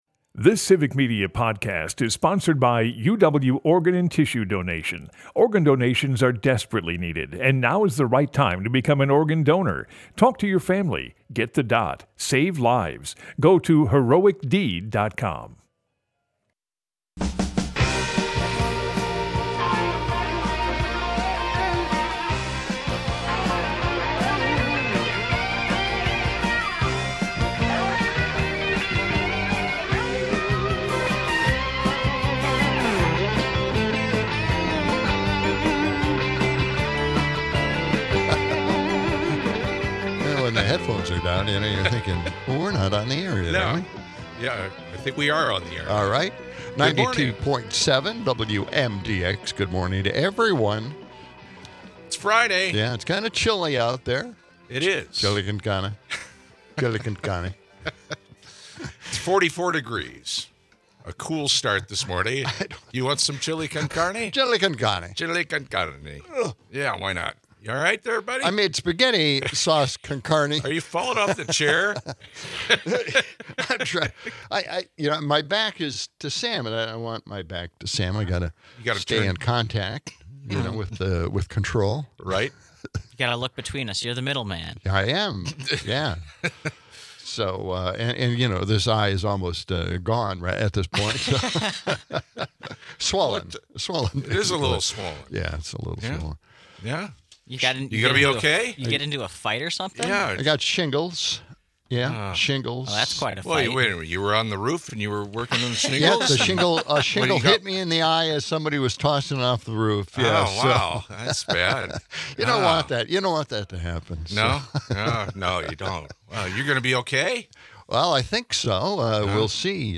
The discussion turns to a failed Oregon ballot measure to provide a UBI, and another in California that would have outlawed forced labor in prisons. Closing out, our spirits get a lift with a clip from the Daily Show, mocking the election of Trump.